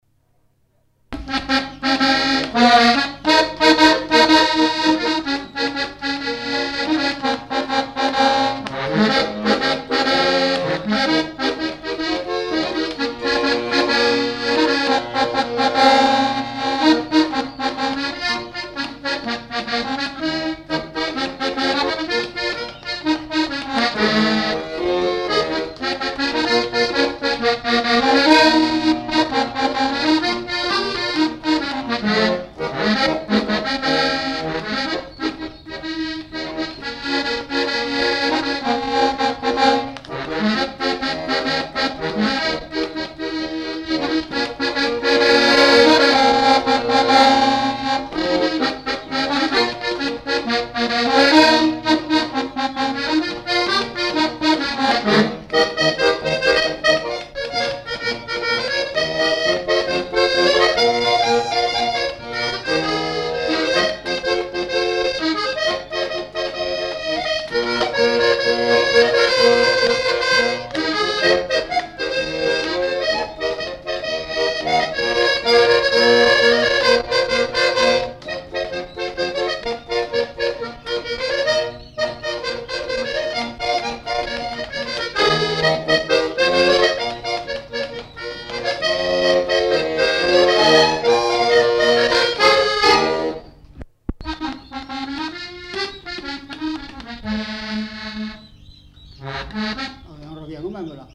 Polka
Aire culturelle : Savès
Lieu : Pompiac
Genre : morceau instrumental
Instrument de musique : accordéon diatonique
Danse : polka